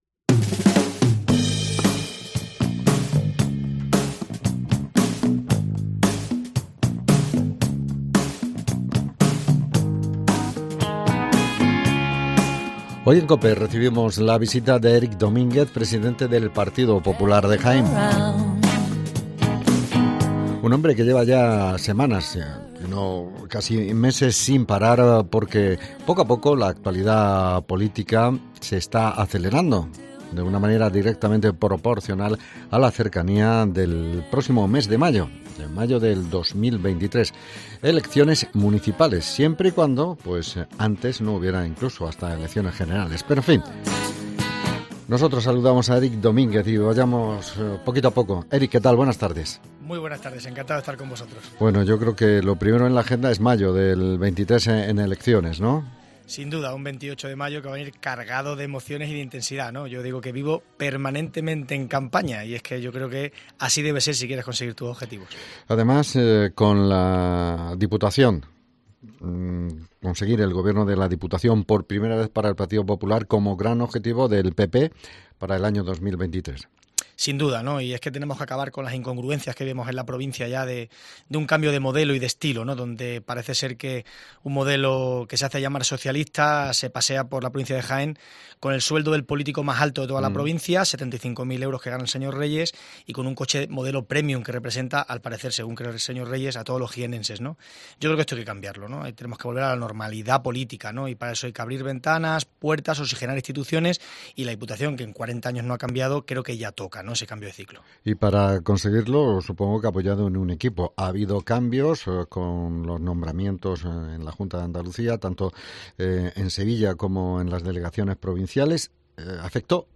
Entrevista con Erik Domínguez